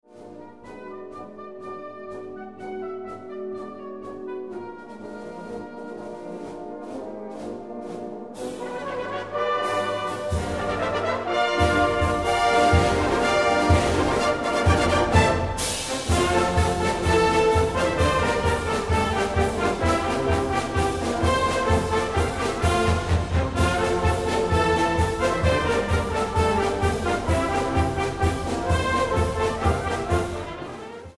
Op deze pagina kunt u verschillende korte fragmenten van de fanfare beluisteren van uitvoeringen op concoursen tussen 1993 en 2007.
Uitvoering: Bondsconcours 2001